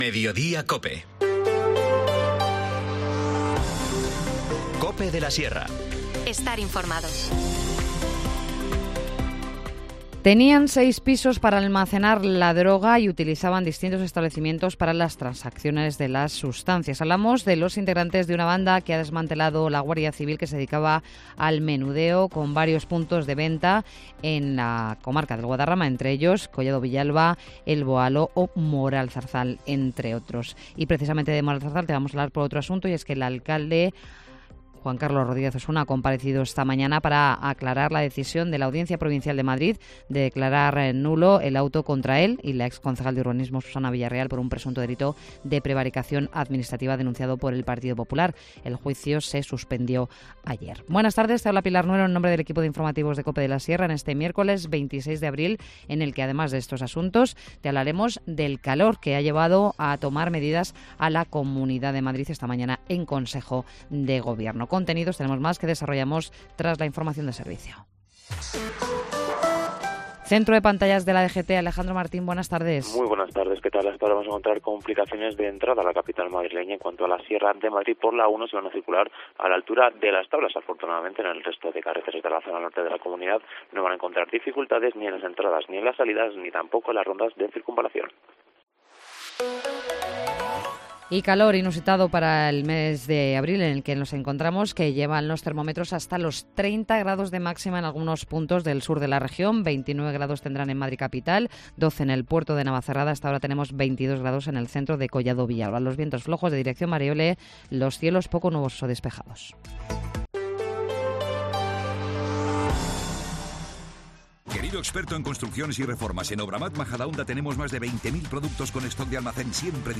Informativo Mediodía 26 abril